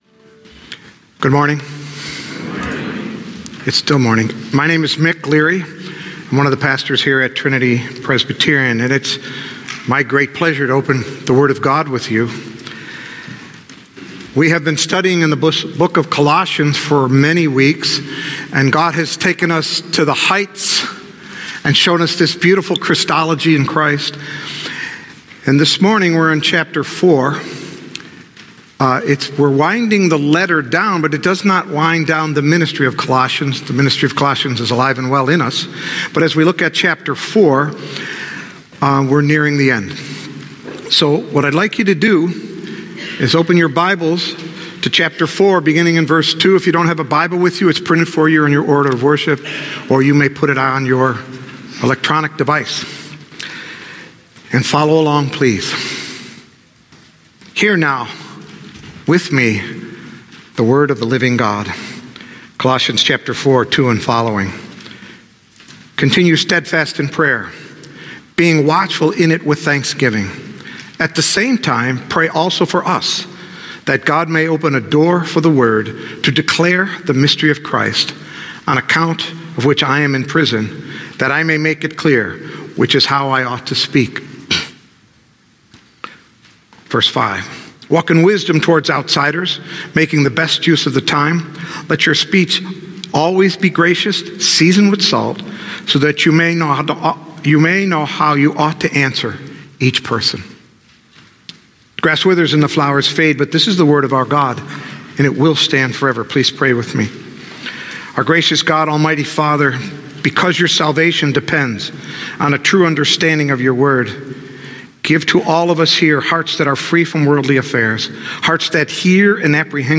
Listen here for sermons from Trinity Presbyterian Church of Charlottesville, VA.